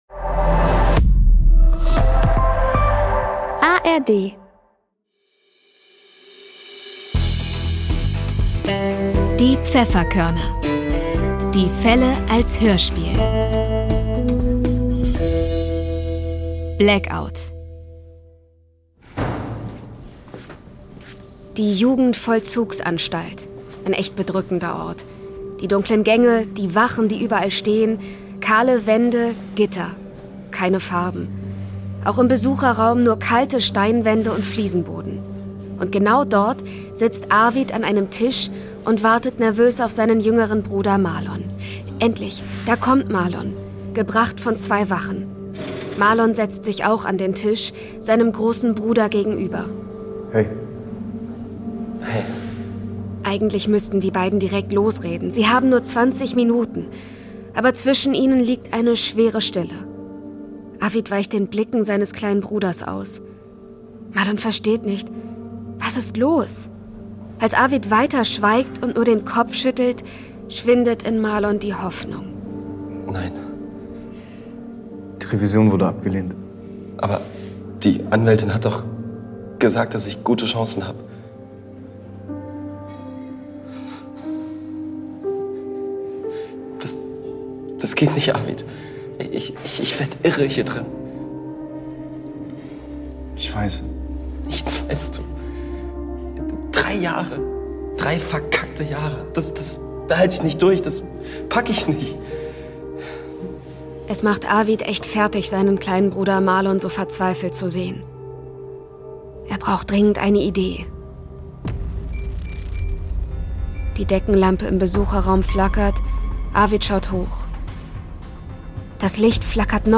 Blackout (12/26) ~ Die Pfefferkörner - Die Fälle als Hörspiel Podcast